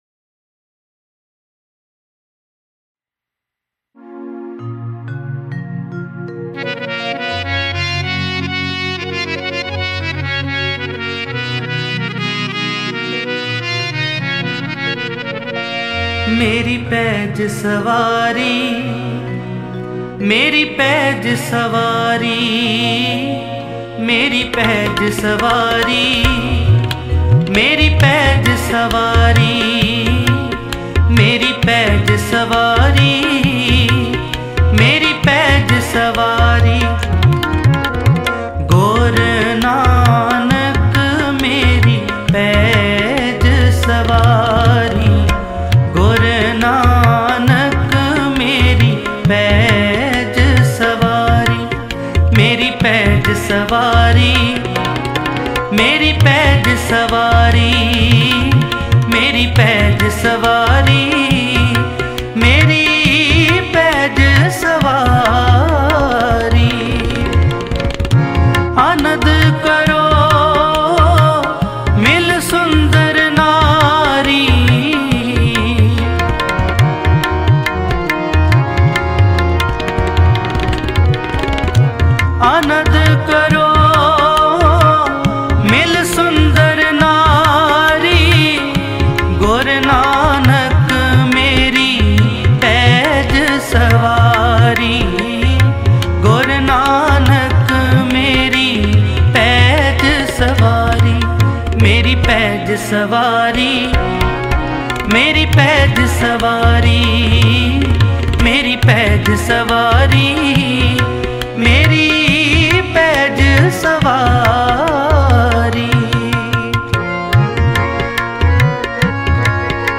Guru nanak Dev Ji De Non Stop Shabad